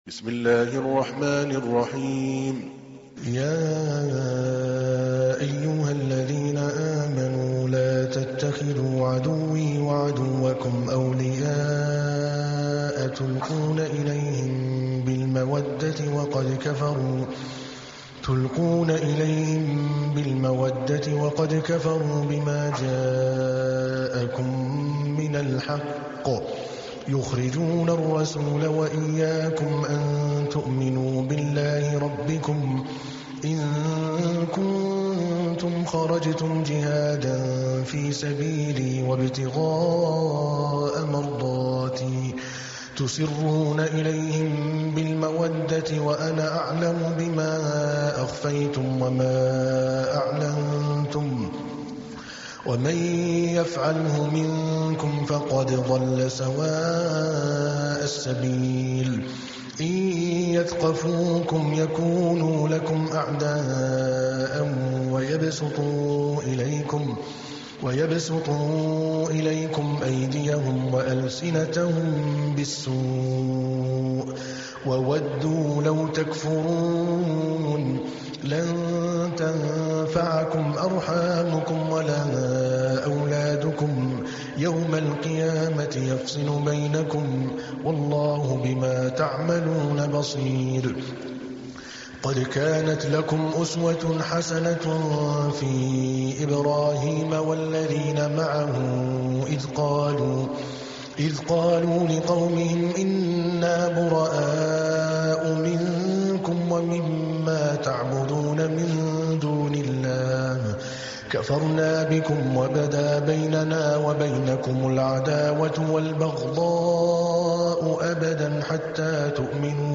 تحميل : 60. سورة الممتحنة / القارئ عادل الكلباني / القرآن الكريم / موقع يا حسين